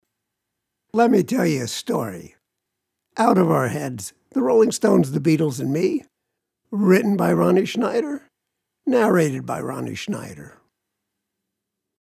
I narrated my book with my raspy voice . The audiobook is now live on audible!